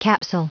Prononciation du mot capsule en anglais (fichier audio)
Prononciation du mot : capsule